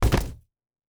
Foley Sports / Football - Rugby
Scrum A.wav